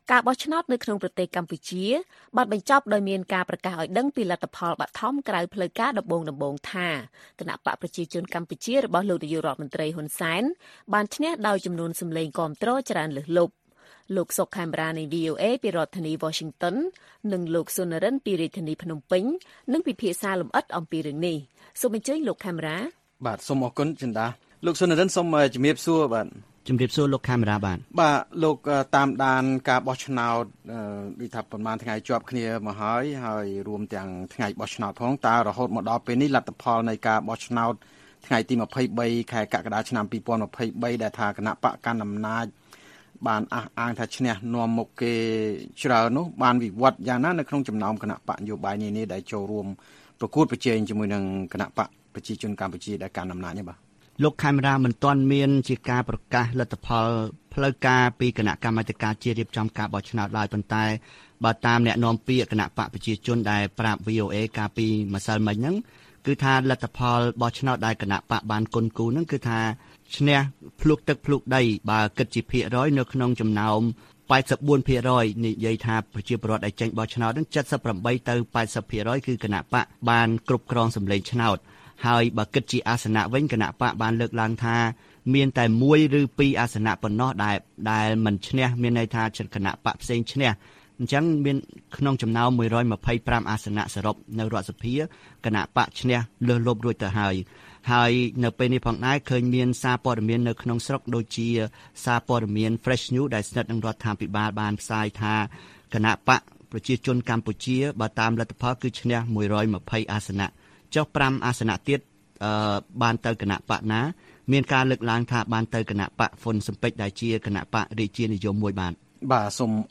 បទសន្ទនា VOA អំពីការបោះឆ្នោតនៅកម្ពុជា៖ លទ្ធផលនិងភាពមិនប្រក្រតីនៃការបោះឆ្នោត